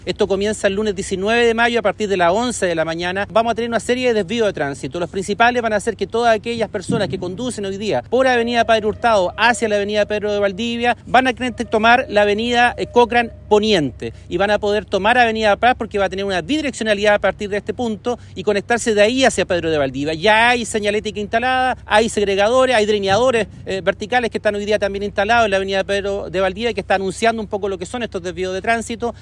Así lo explicó el seremi de Obras Públicas, Hugo Cautivo.